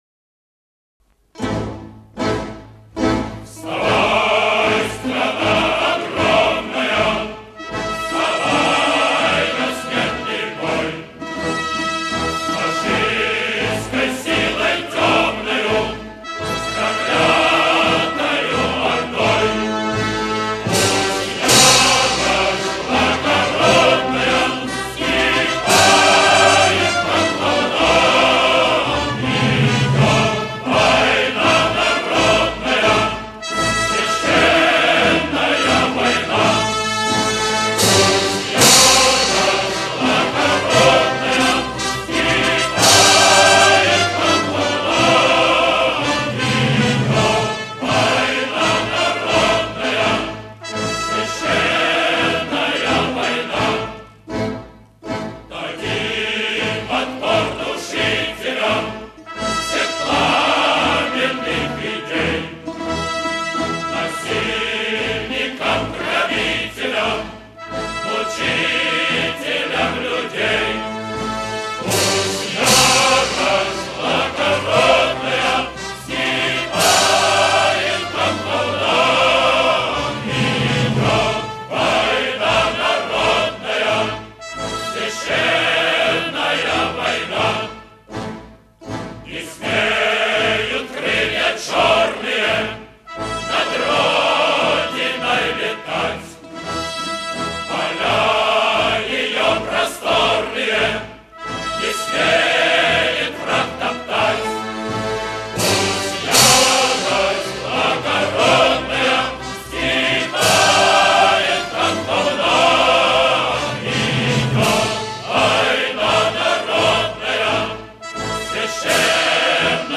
В полутемном зале звучит музыка "Священная война» (